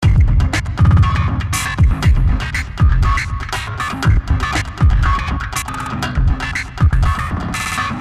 描述：沉重的低音汤姆节拍
标签： 120 bpm Fusion Loops Drum Loops 1.35 MB wav Key : Unknown
声道立体声